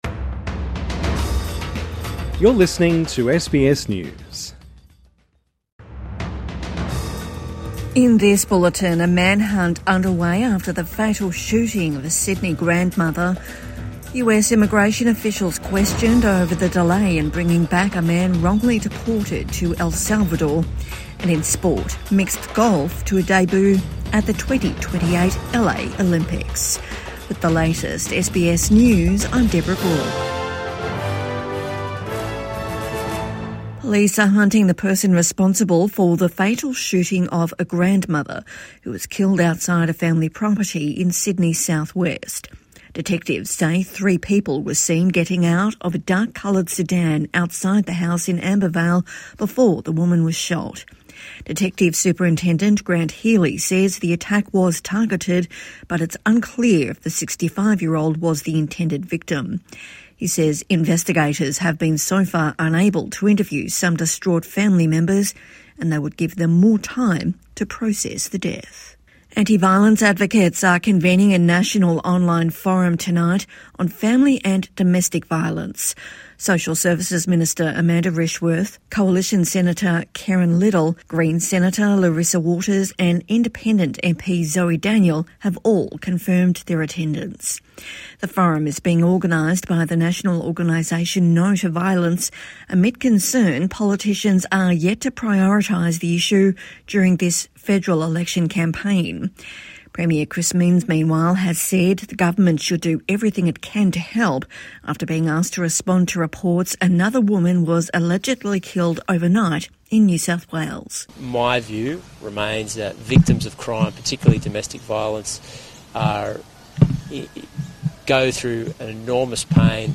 Midday News Bulletin 16 April 2025